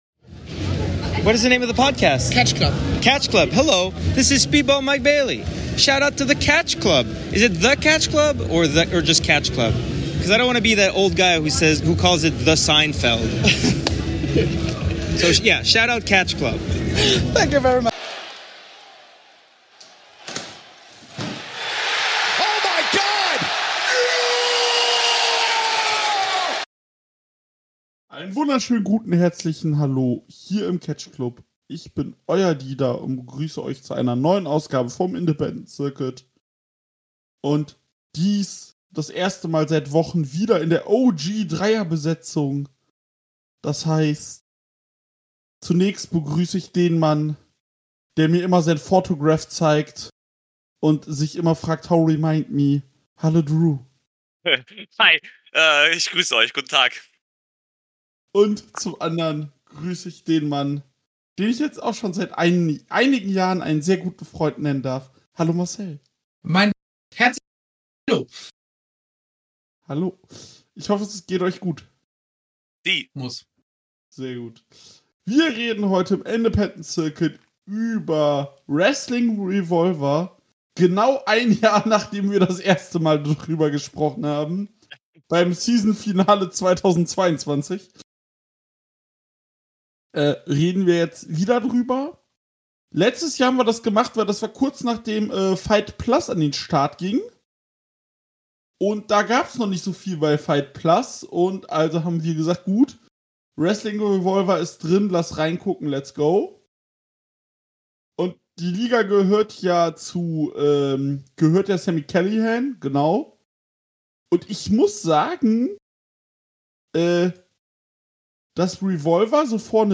Wir sprechen im Catch-Club zum 2. mal über Wrestling Revolver die Liga von Sami Callihan. Diese hatte ihre letzte Show des Jahres und darauf sind wir in unserer dreier Runde drauf eingegangen.